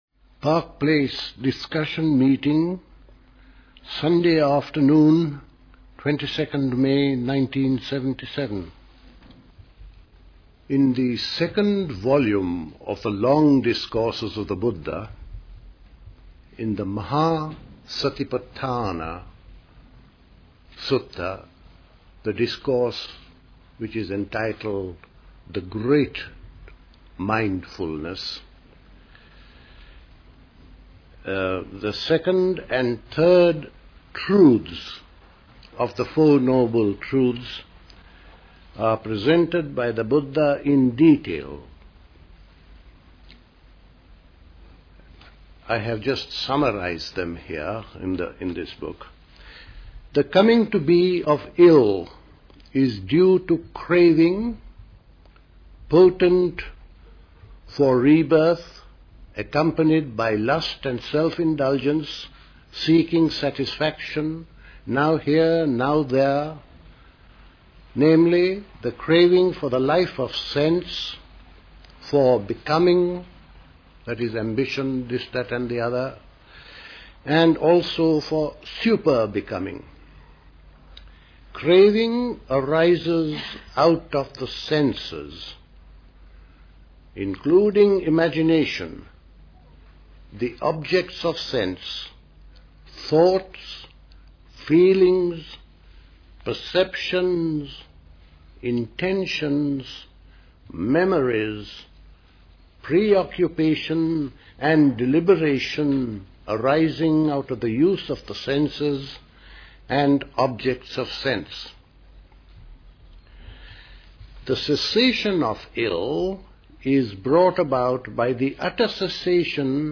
Park Place Pastoral Centre, Wickham, Hampshire
The Park Place Summer School Talks